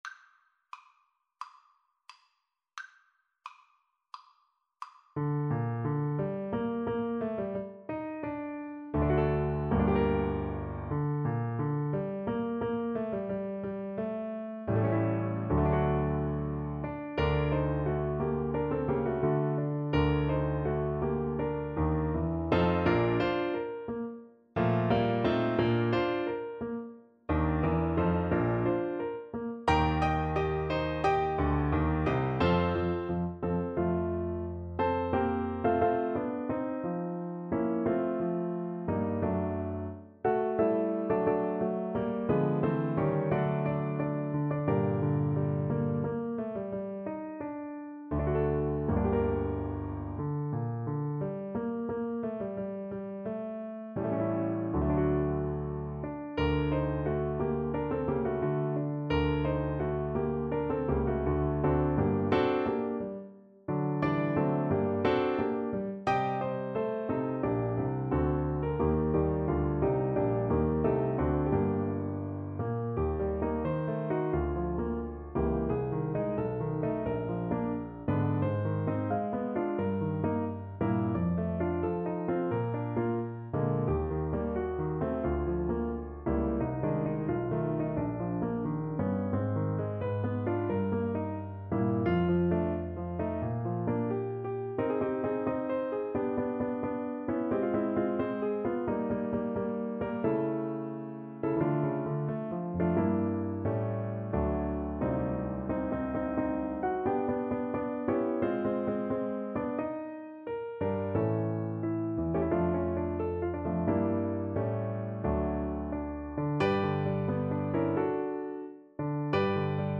=88 Nicht schnell =100